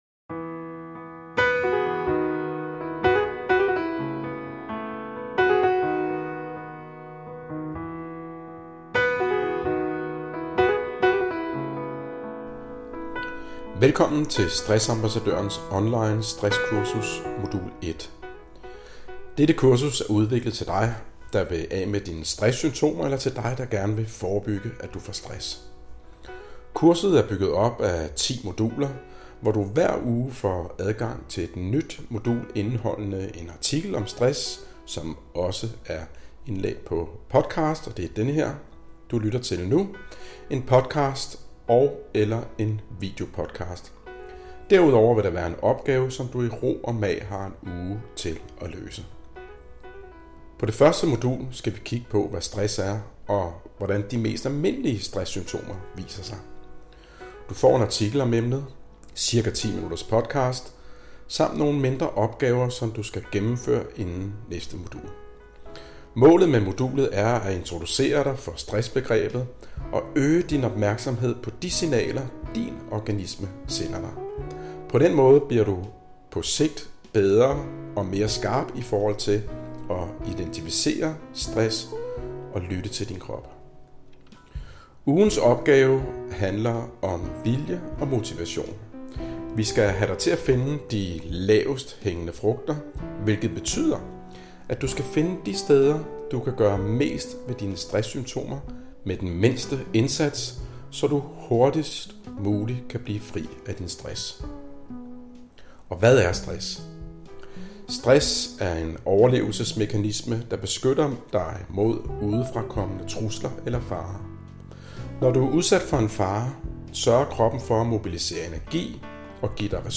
Artikel-Indlaest-Modul-1-artikel-indlaest.mp3